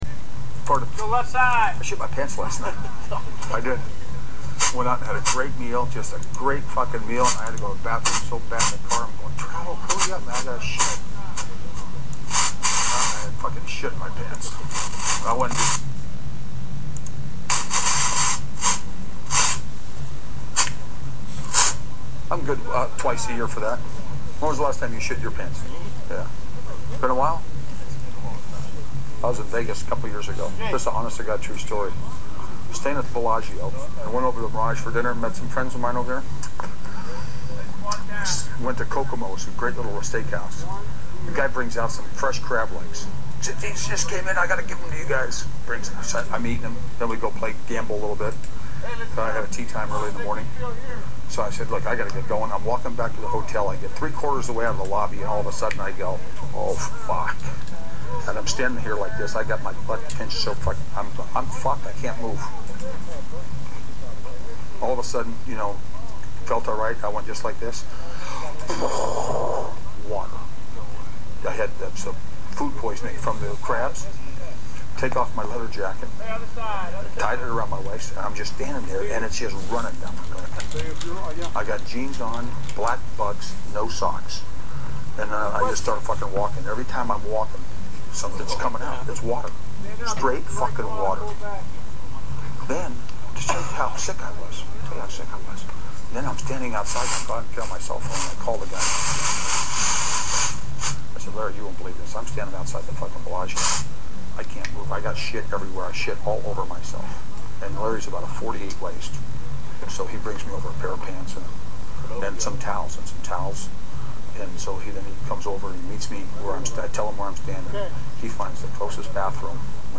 It’s one thing to shit yourself, but it’s another to tell it to an audience and make it entertaining.